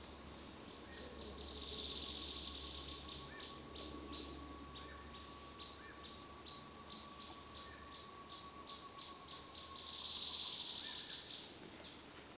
Arapaçu-rajado (Xiphorhynchus fuscus)
Nome em Inglês: Lesser Woodcreeper
Fase da vida: Adulto
Localidade ou área protegida: Parque Provincial Urugua-í
Condição: Selvagem
Certeza: Gravado Vocal
chinchero-enano.mp3